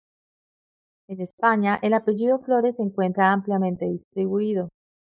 a‧pe‧lli‧do
/apeˈʝido/